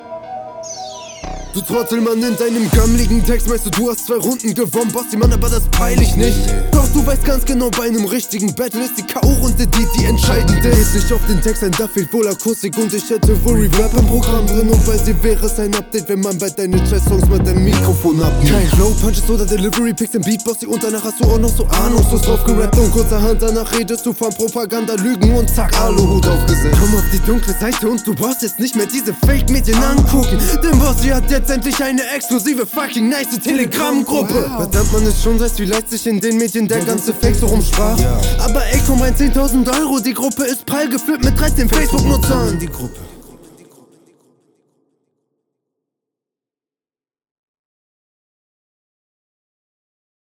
Das klingt direkt nochmal besser als dein Rest hier im Battle.
Fan den verschwörungsangle bisschen komusch und random und zu viel nervige höhen auf der vocal